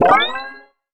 collect_item_23.wav